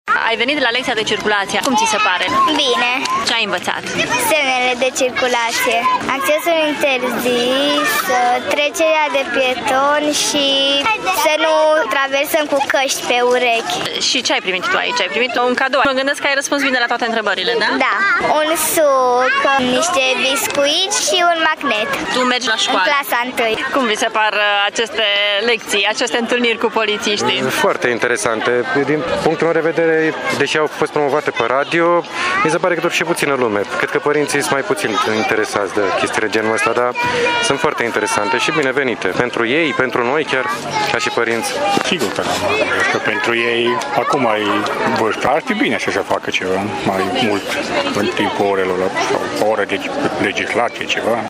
Pe de altă parte, părinții așteaptă și ei mai multă implicare de la sistemul de învățământ pentru educația rutieră a copiilor: